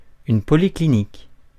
Ääntäminen
Ääntäminen France: IPA: [pɔ.li.cli.nik] Haettu sana löytyi näillä lähdekielillä: ranska Käännös 1. policlínico {m} Suku: f .